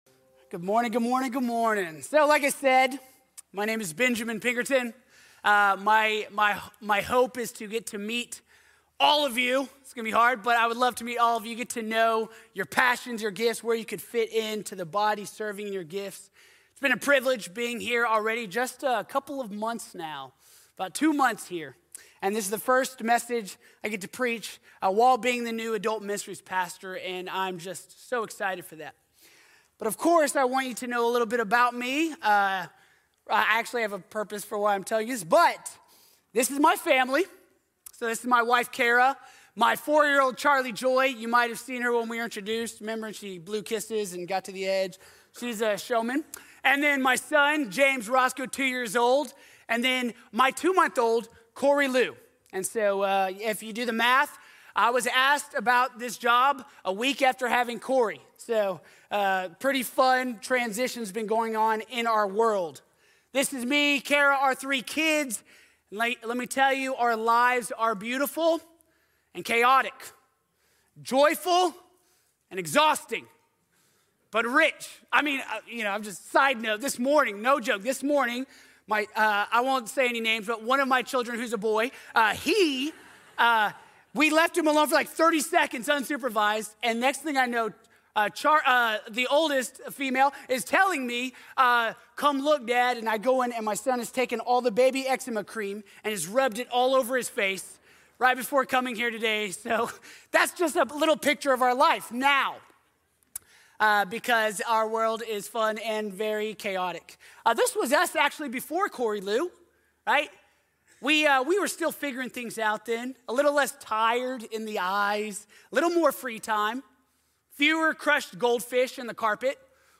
Costly Worship | Sermon | Grace Bible Church